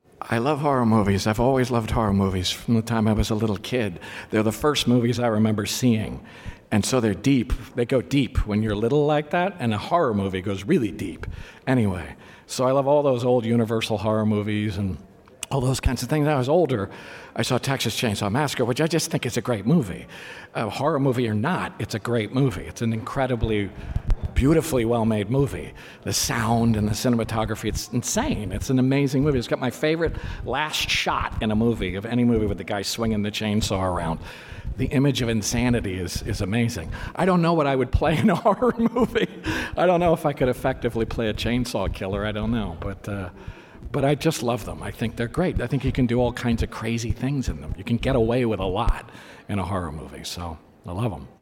Paul Giamatti revealed during a red carpet interview for The Golden Globes that he wants to be in a horror film. That interesting tidbit was picked up backstage, as he was asked why that Tobe Hooper classic is a personal favorite!